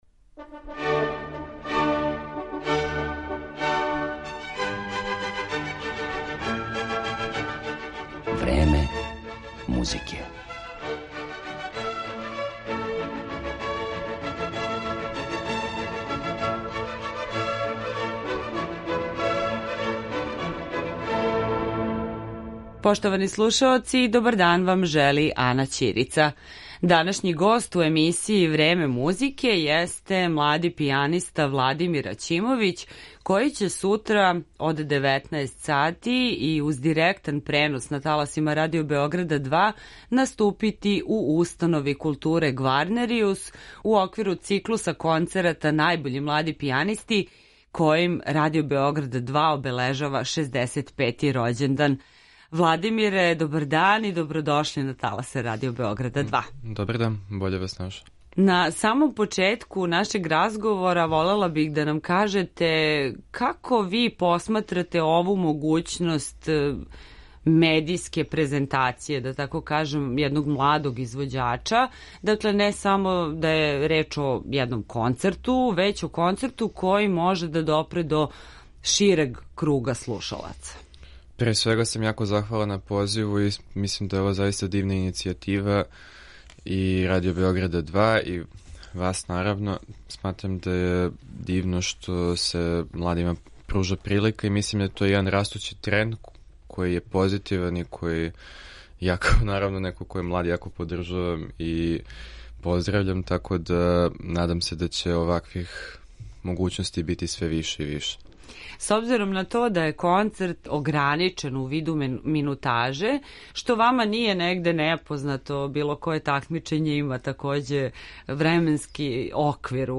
Пијаниста